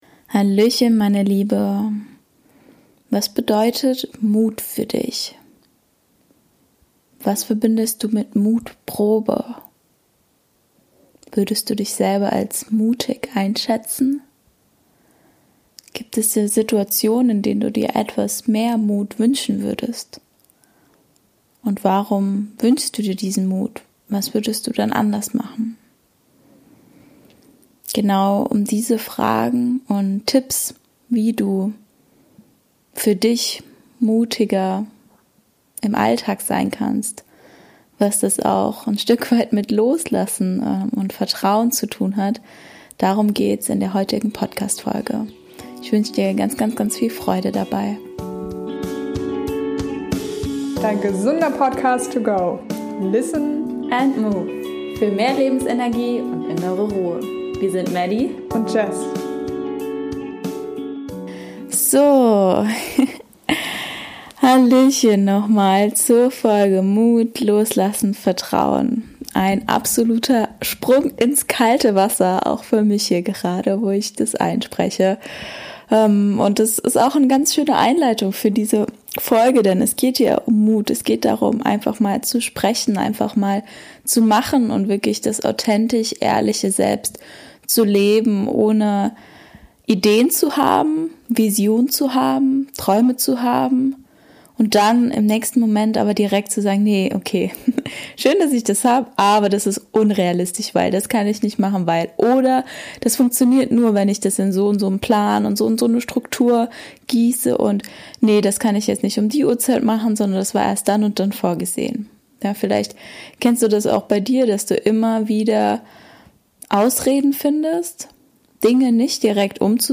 eine ruhige, kurze und tiefgreifende Abschlussmeditation